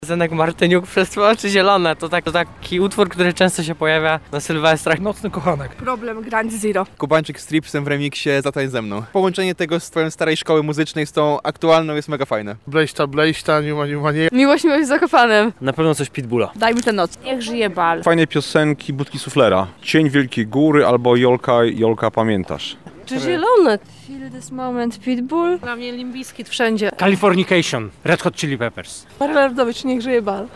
Zapytaliśmy lublinian bez jakiego utworu albo wykonawcy nie wyobrażają sobie ostatniego dnia w roku:
sonda